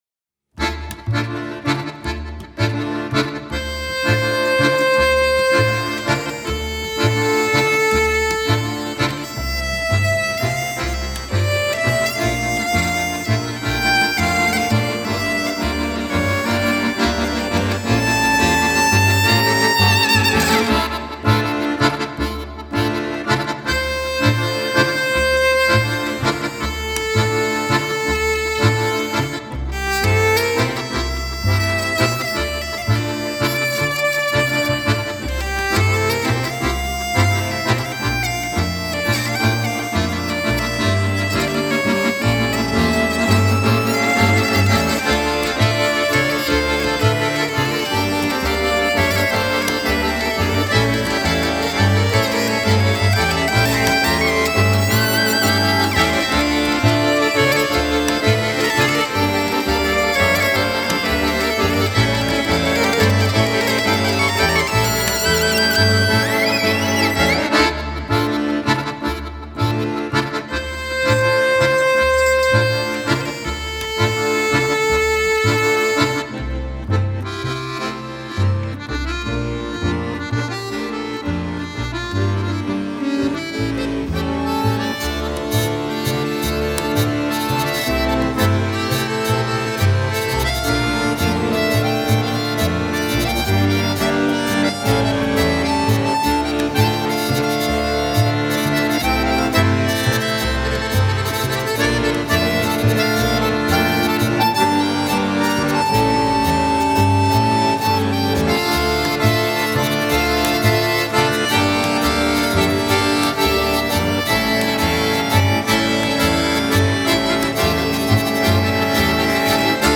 ACCORDION MP3
· CHAMBER MUSIC
hurdy gurdy
contrabass